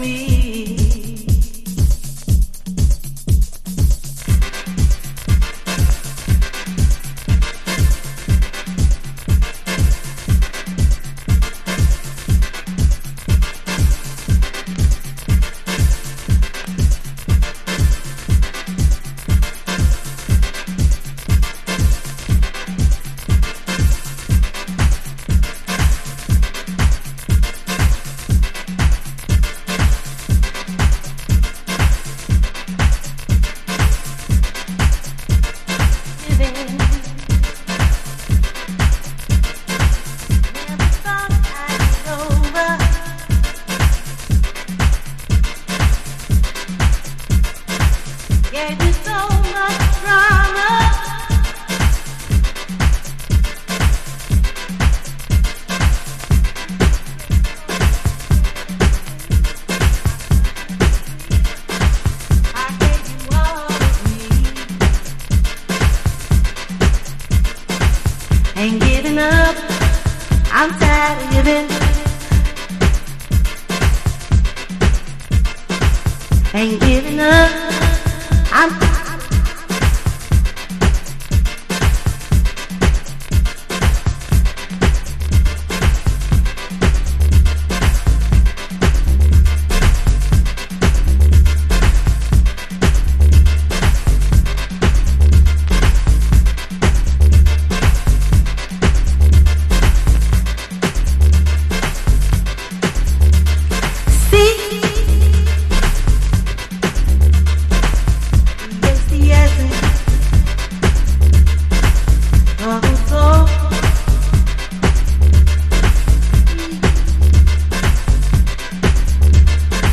Chicago Oldschool / CDH
この時期の荒々しさと浮遊感が同居したサウンドはたまんないです。